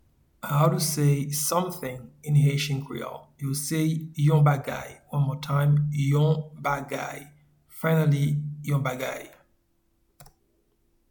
Pronunciation and Transcript:
Something-in-Haitian-Creole-Yon-bagay.mp3